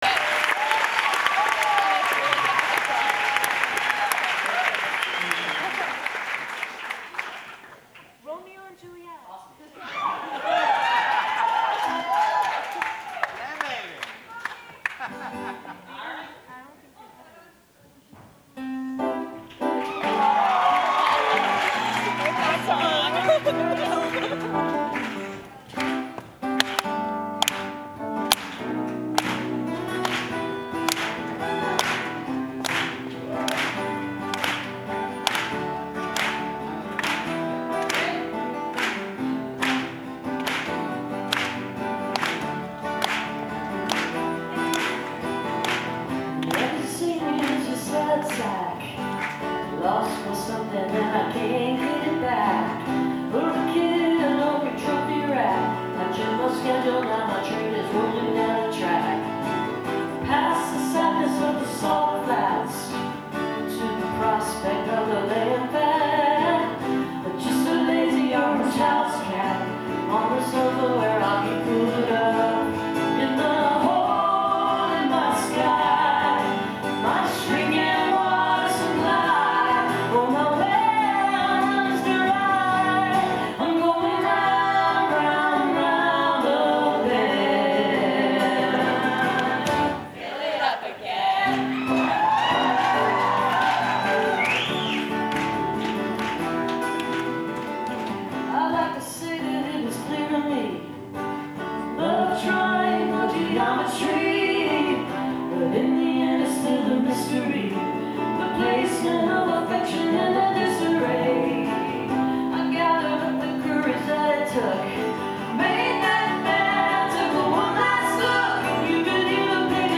zankel hall - carnegie (acjw) - new york, new york